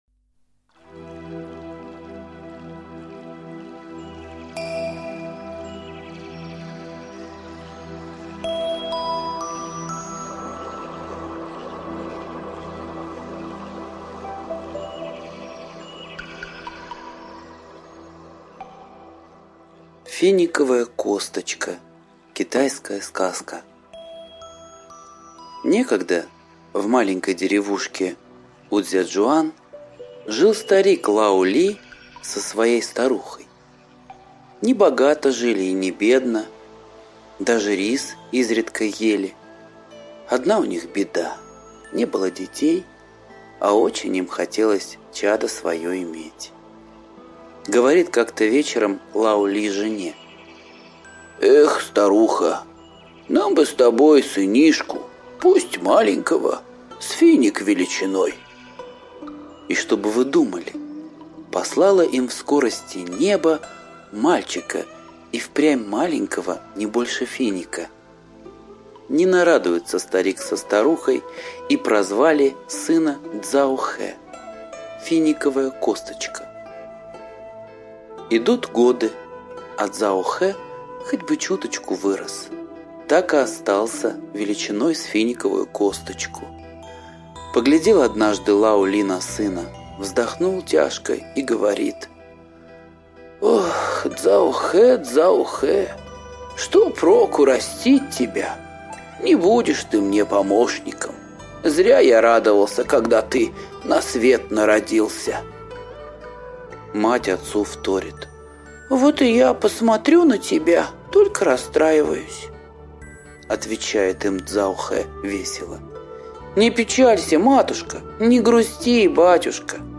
Финиковая косточка - восточная аудиосказка - слушать онлайн